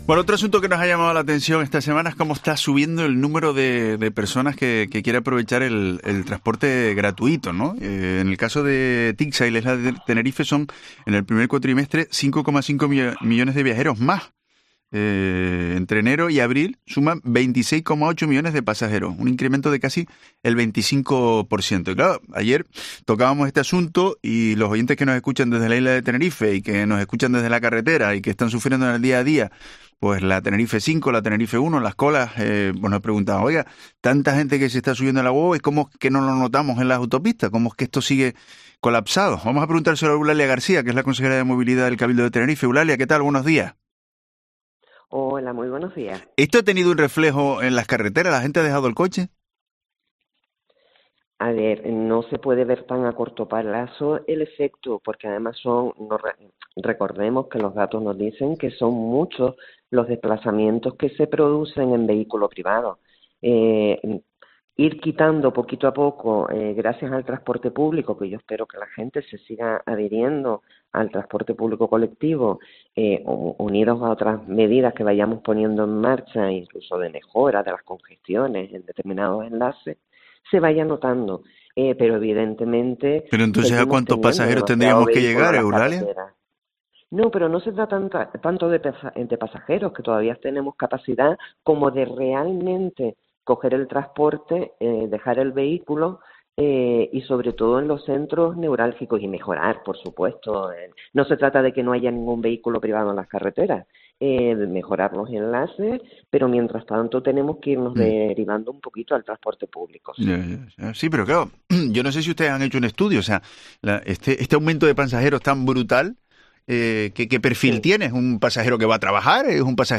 La concejala de Movilidad del Cabildo de Tenerife, Eulalia García, explicaba en los micrófonos de COPE Canarias la delicada situación que se produce en la playa de Las Teresitas, donde se reforzó la línea, pero no produjo el efecto deseado .